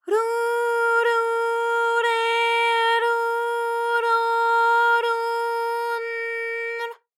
ALYS-DB-001-JPN - First Japanese UTAU vocal library of ALYS.
ru_ru_re_ru_ro_ru_n_r.wav